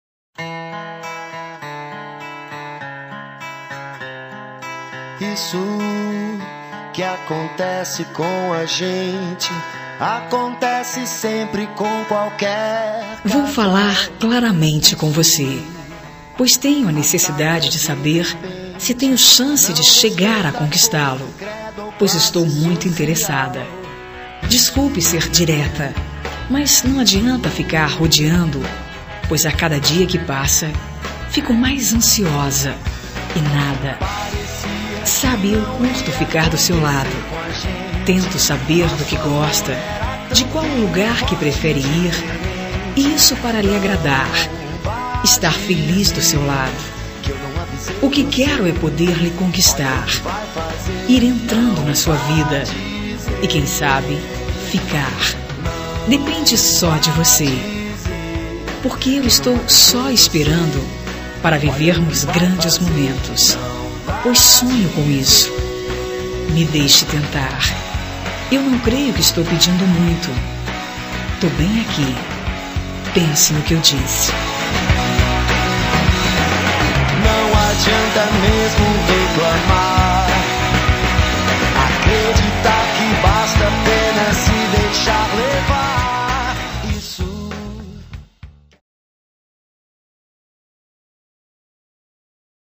Telemensagem de Conquista – Voz Feminina – Cód: 140104